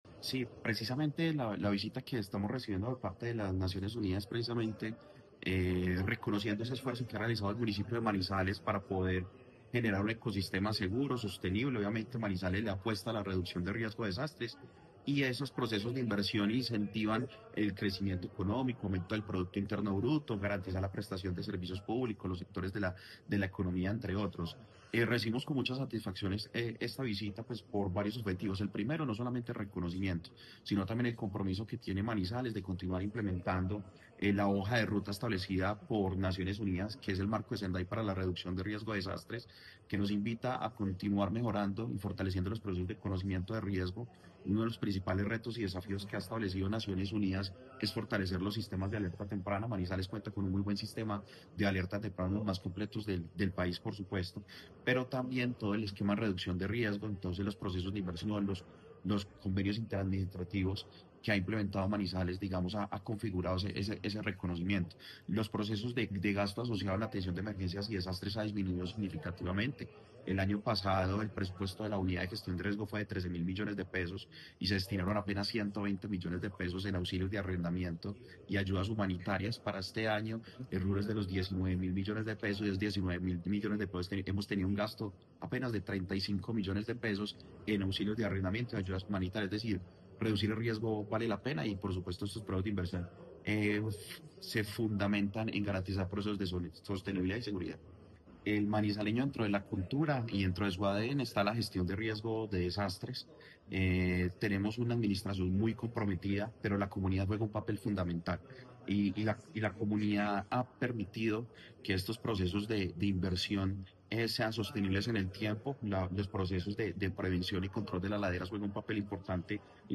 Diego Armando Rivera, director de Gestión del Riesgo de Manizales